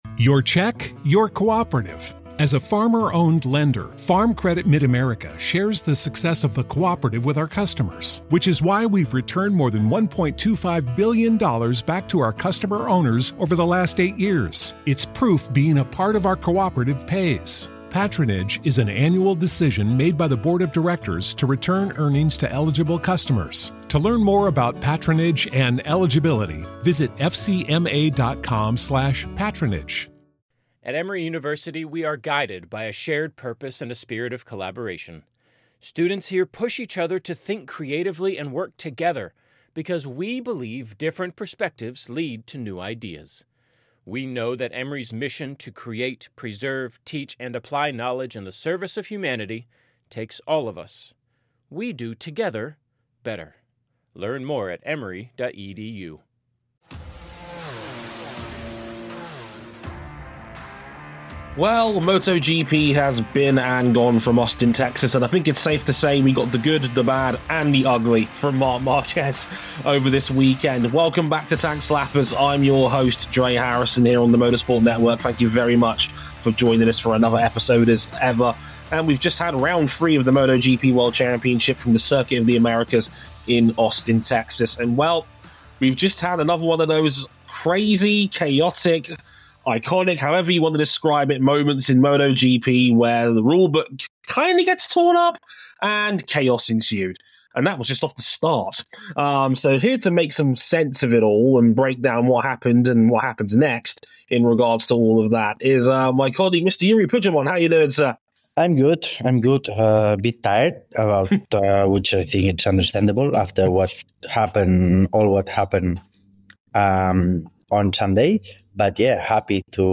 The show also features a sit-down interview with Suzuki legend and 1993 MotoGP World Champion Kevin Schwantz, who discusses the dynamics between Ducati teammates Bagnaia and Marquez, where brother Alex fits into the title fight.